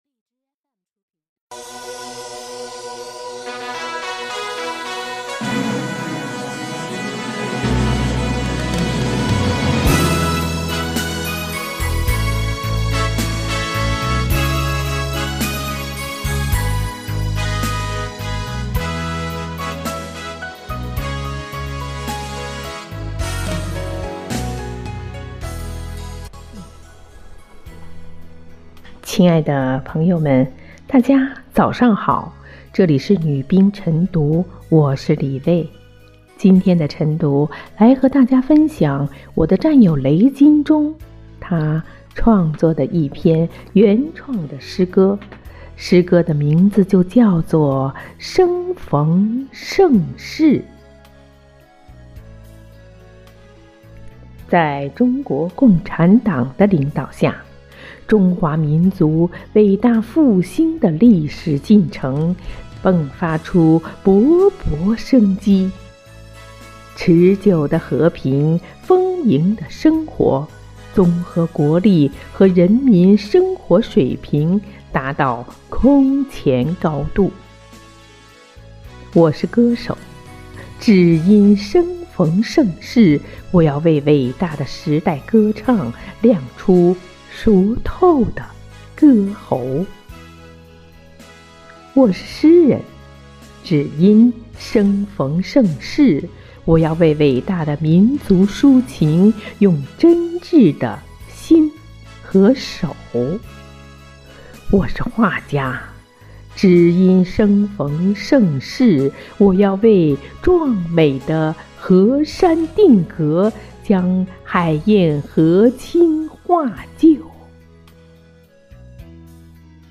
每日《女兵诵读》生逢盛世